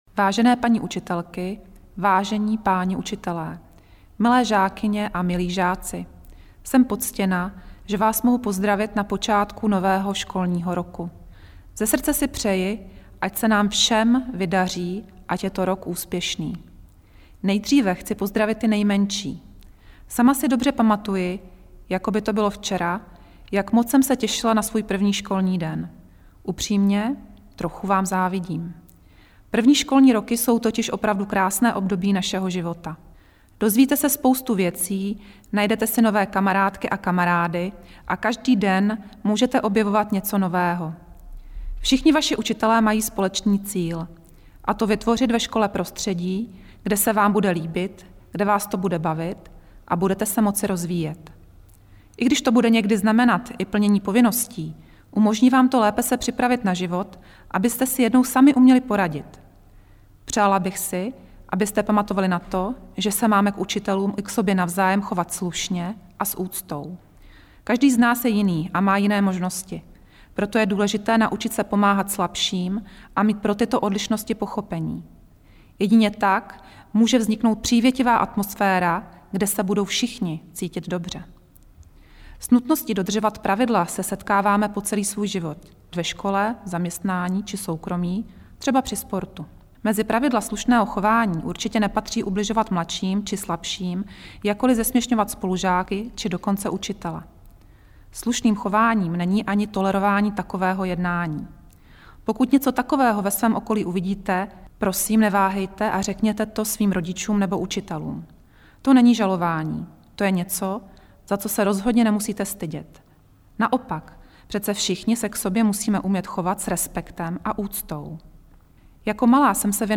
projev ministrině Valachové V1.mp3